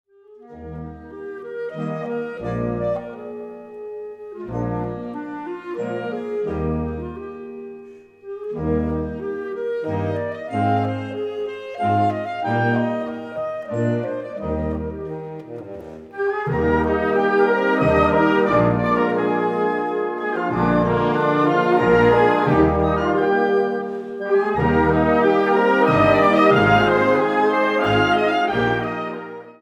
Gattung: Suite
Besetzung: Blasorchester